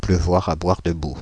Ääntäminen
Synonyymit pleuvoir à verse pleuvoir à seaux mouiller à siaux mouiller à verse mouiller à seaux mouiller à boire debout pleuvoir à siaux Ääntäminen Paris: IPA: [plø.vwaʁ‿a bwaʁ də.bu] France (Île-de-France): IPA: /plø.vwaʁ‿a bwaʁ də.bu/